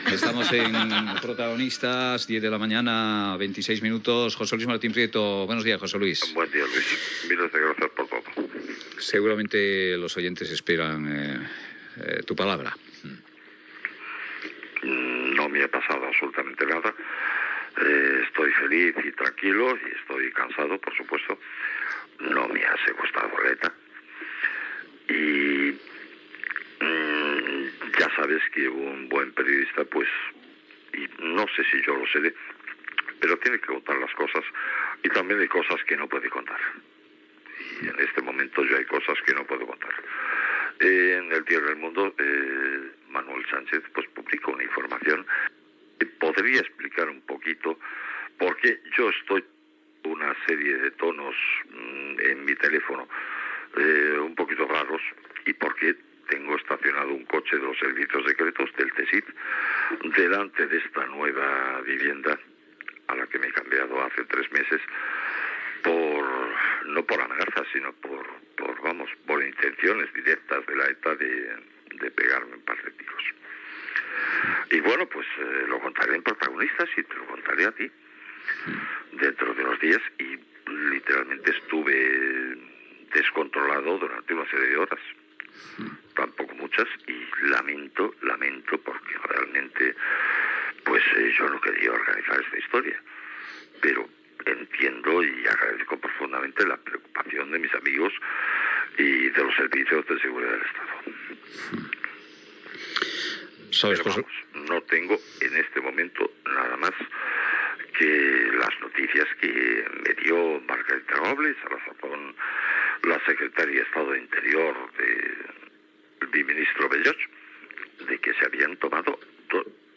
Intervencions de José Luis Martín Prieto després de la notícia del seu fals segrestament per ETA.
Info-entreteniment